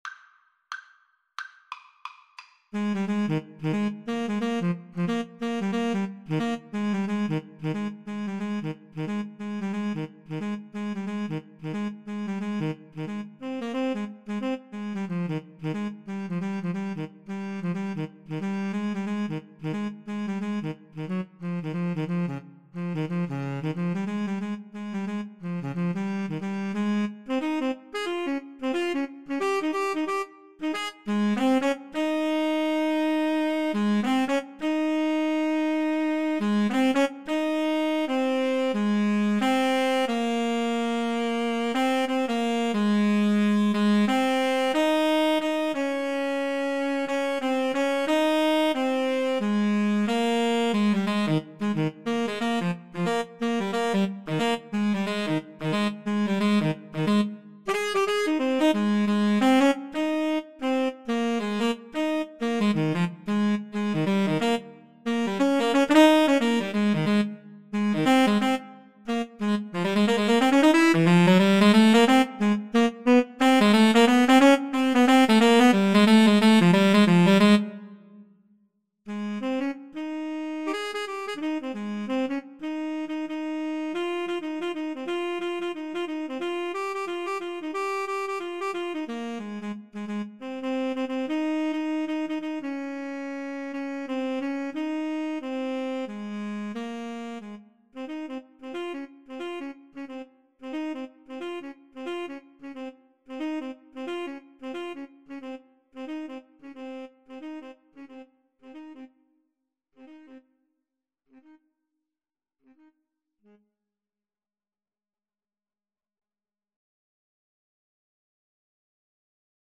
Fast and with a swing =c.90